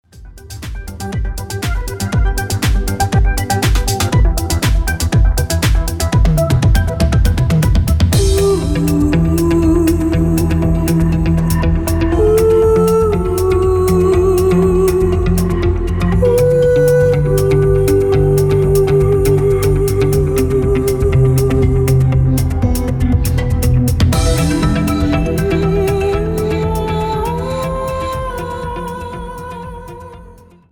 • Type : Instrumental
• Bpm : Allegretto
• Genre : Techno/ Old school